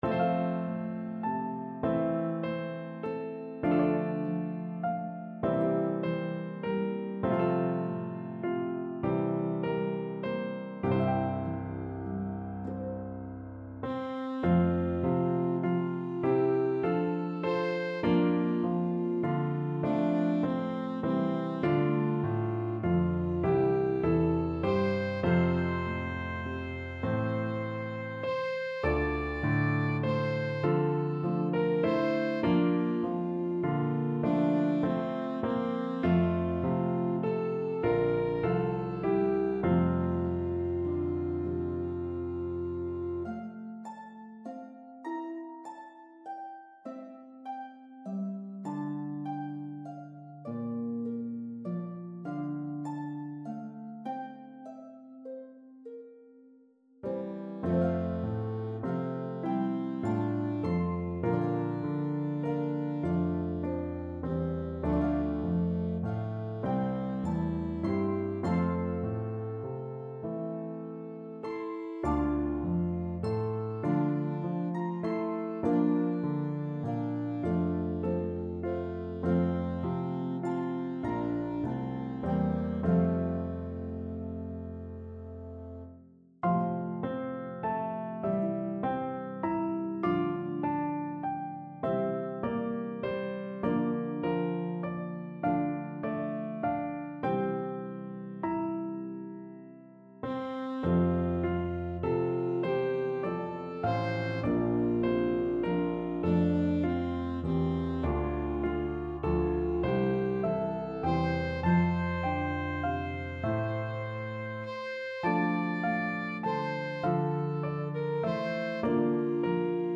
A meditative Appalachian melody